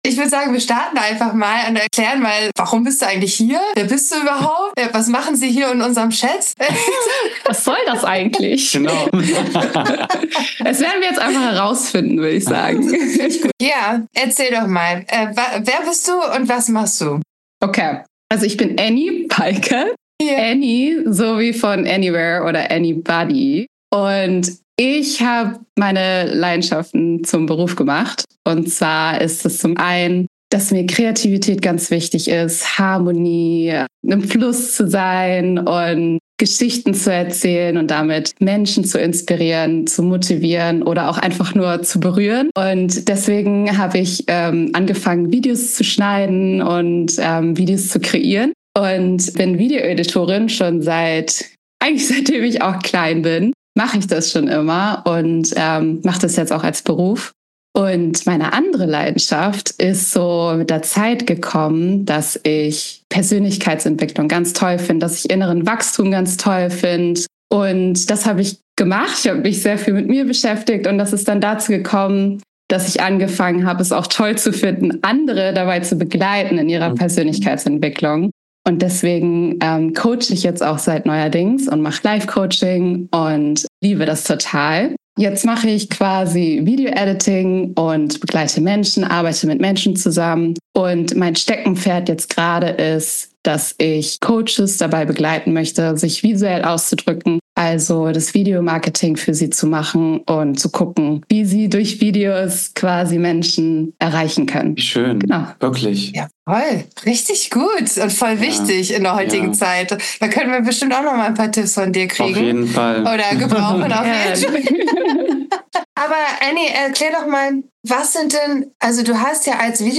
Wie Videos & Coaching dein Business stärken – Interview